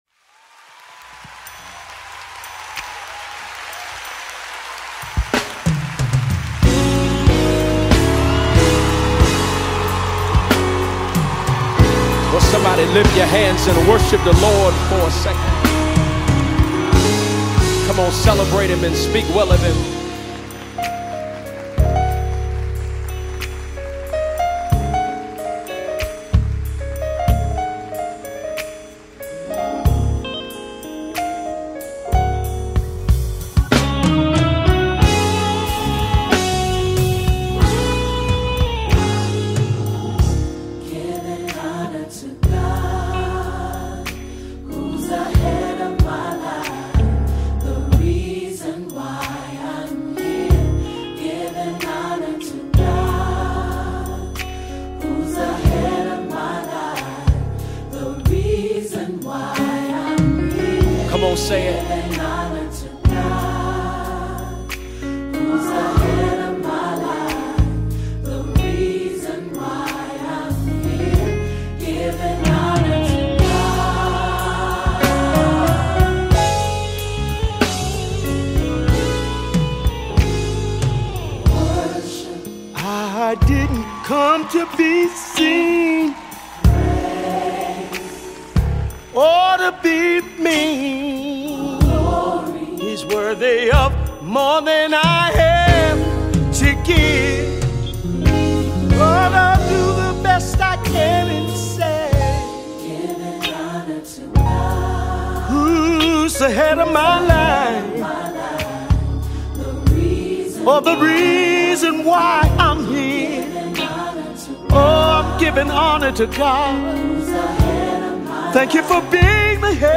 Mp3 Gospel Songs
With its dynamic arrangement and impassioned vocals